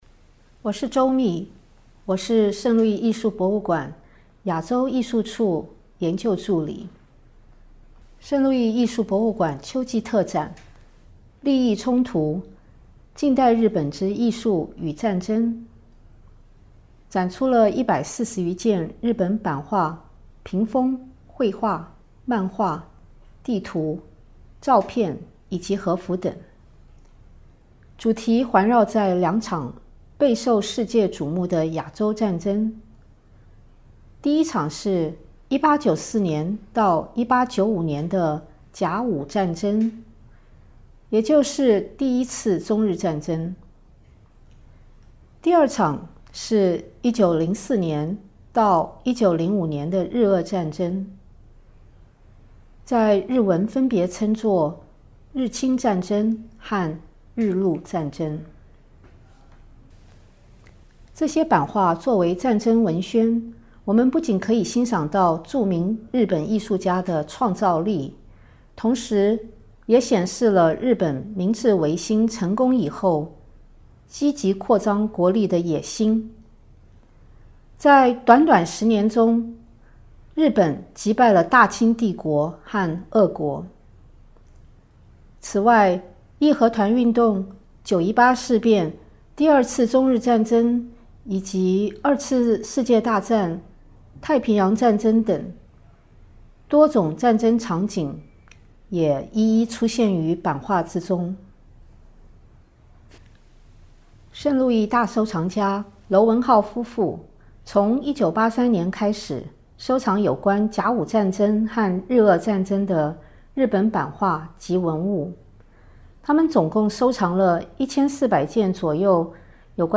This audio guide features an introduction in both English and Chinese, and expert commentary on 8 works of art from the exhibition.